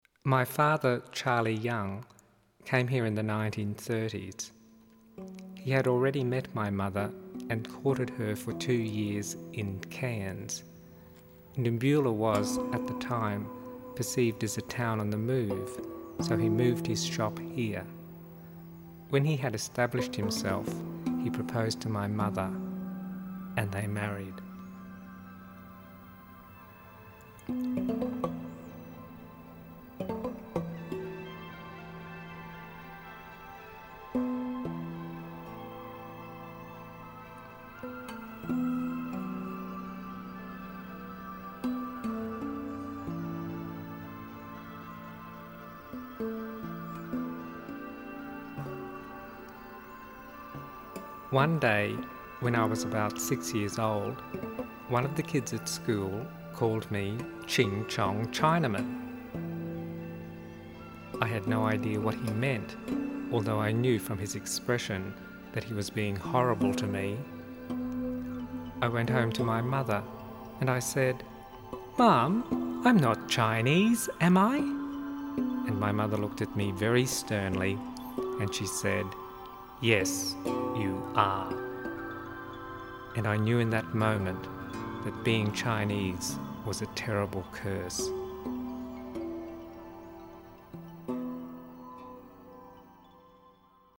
A lush musical painting of the Australian landscape.